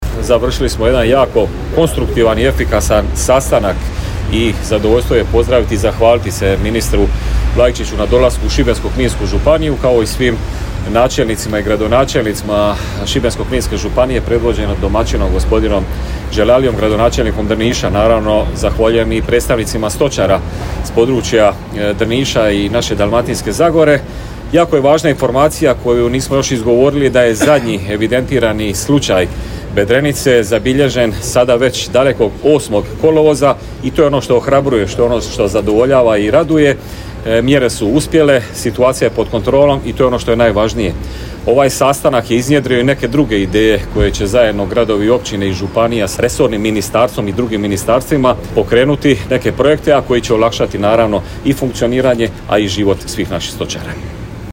Župan Paško Rakić kazao je kako je zadnji zabilježen slučaj datira još 08. kolovoza: